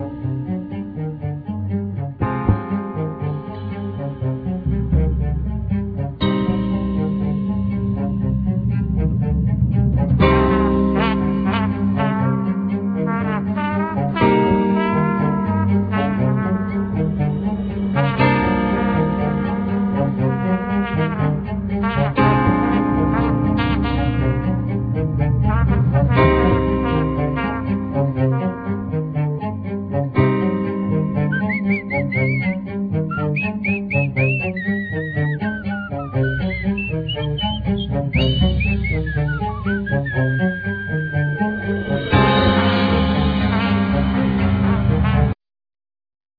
Piano,Keyboards,Trumpet,Vocal,whistling
Drums,Accordion,Vocal
Bass guitar
Alt sax,Clarinet
Cello
Cimbal